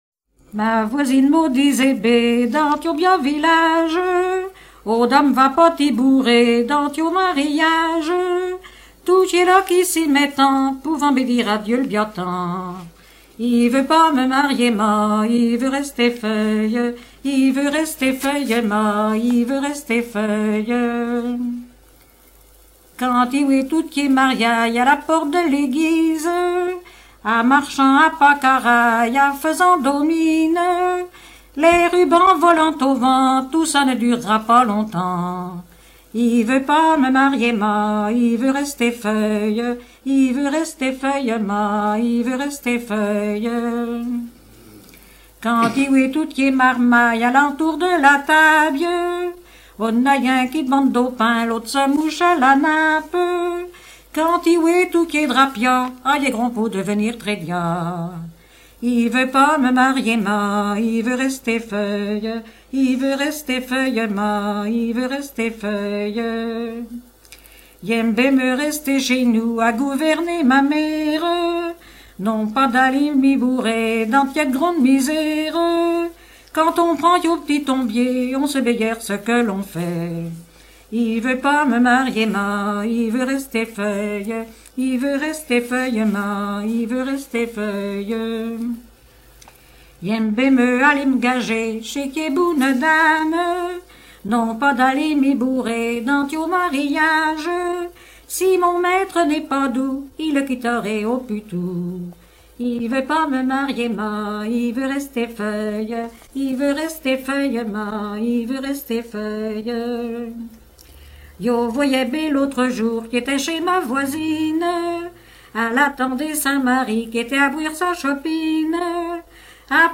Genre dialogue
Catégorie Pièce musicale éditée